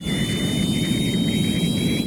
CosmicRageSounds/shipmove1.ogg_64f75623 at 4f151c074f69b27e5ec5f93e28675c0d1e9f0a66